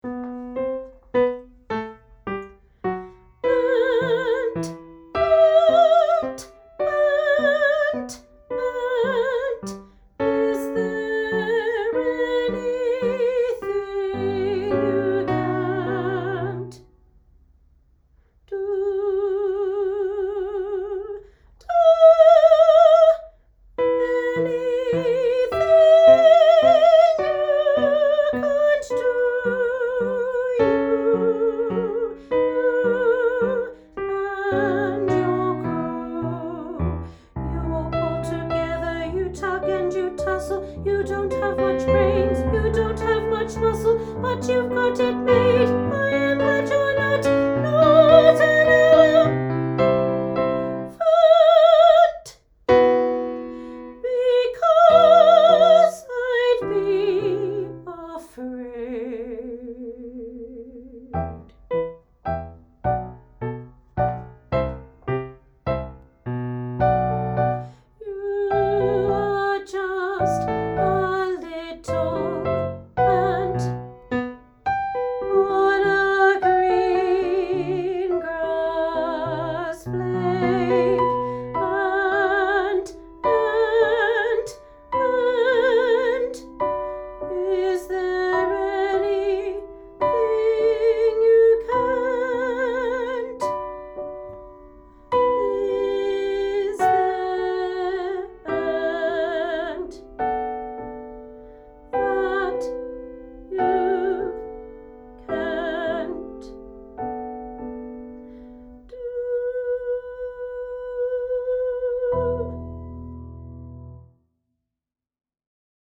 The-Ant_Jnr-Soprano.Part-1.mp3